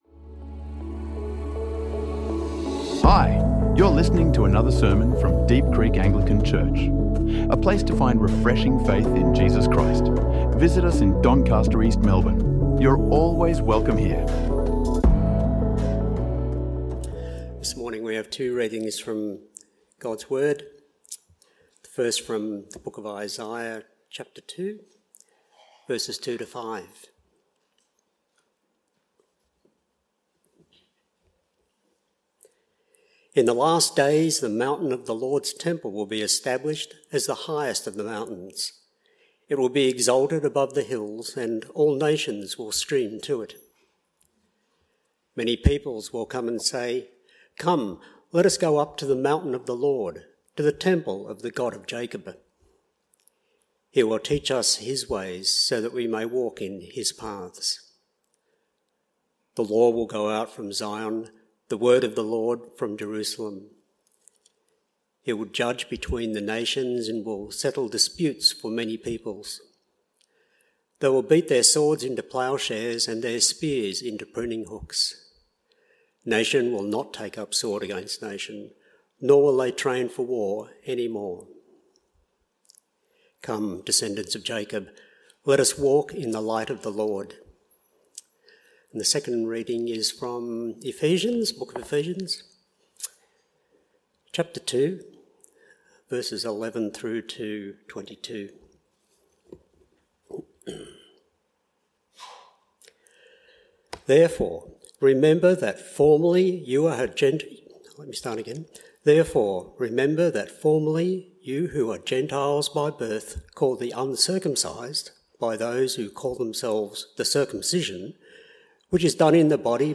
Jesus is Good for A Divided World | Sermons | Deep Creek Anglican Church